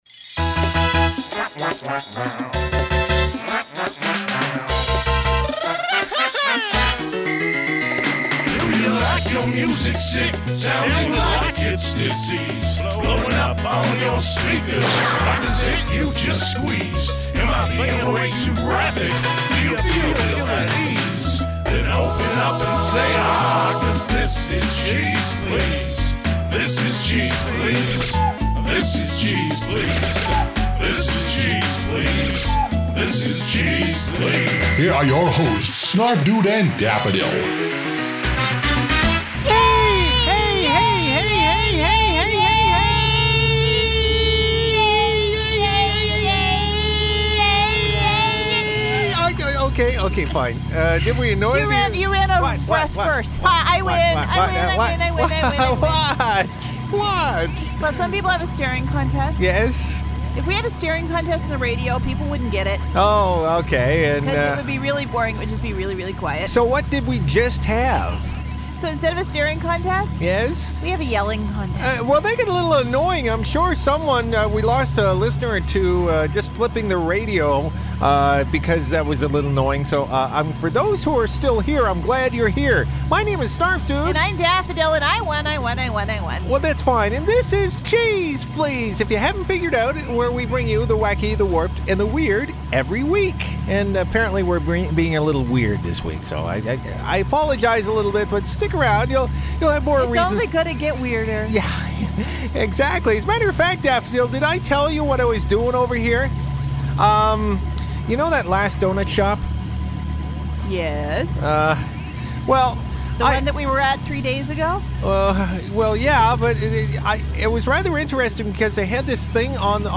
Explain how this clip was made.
Stereo